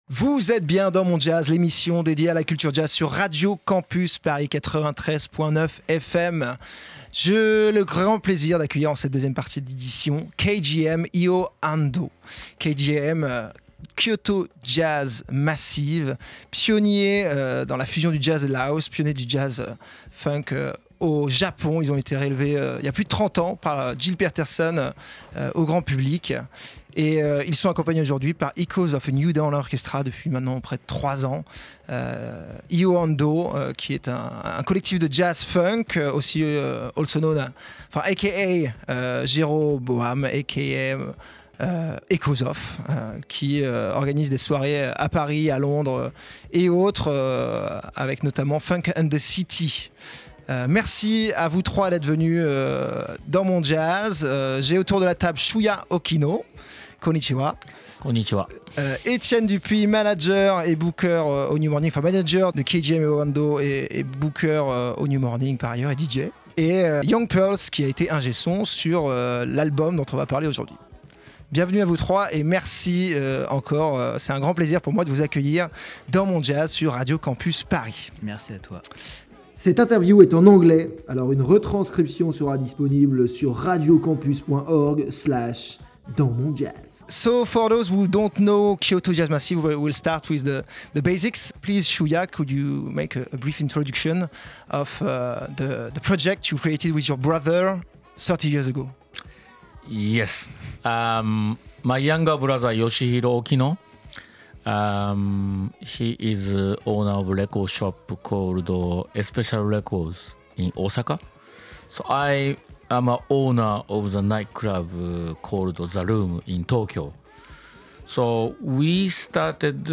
Ecoute l'interview complète de Kyoto Jazz Massive et Echoes Of A New Dawn Orchestra (saison 2025/2026 · Episode 1)